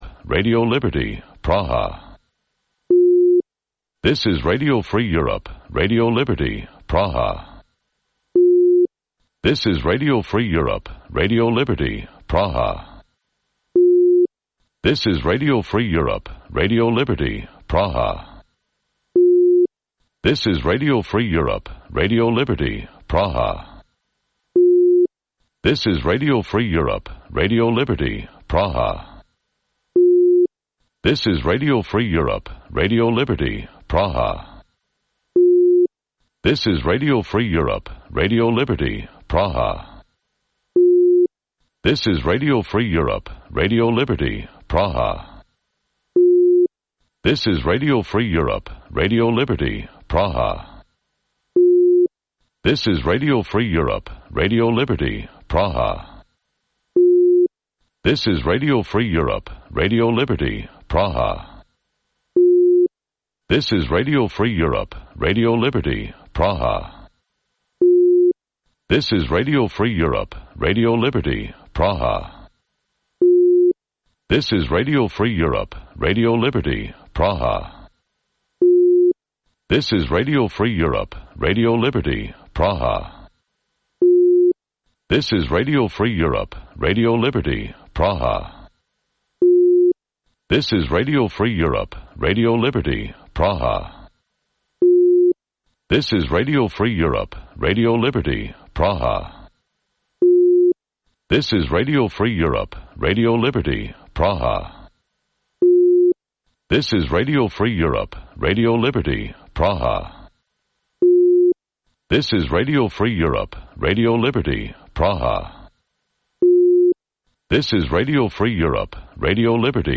Вечірній ефір новин про події в Криму. Усе найважливіше, що сталося станом на цю годину.